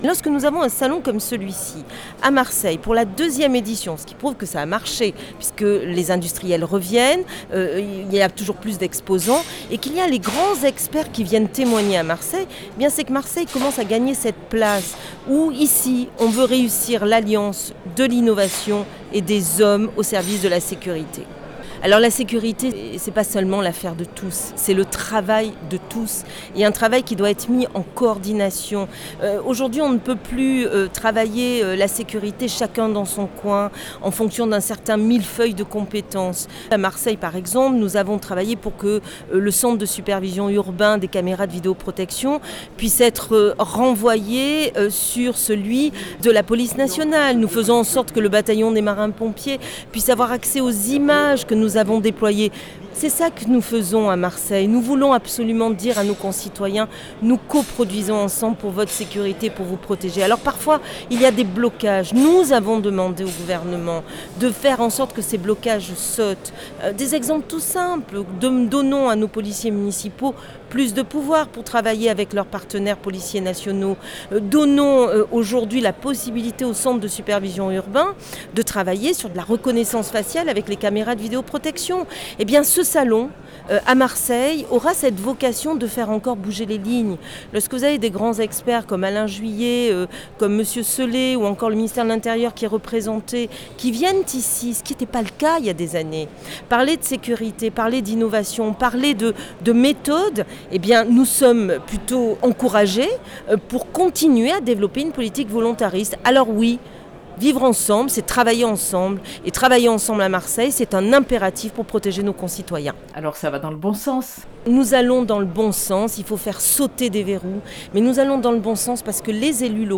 AccesSecurity, le Salon Méditerranéen de la sécurité globale, dont la cybersécurité, a ouvert ses portes, ce mercredi 29 mars pour 3 journées au Parc Chanot à Marseille.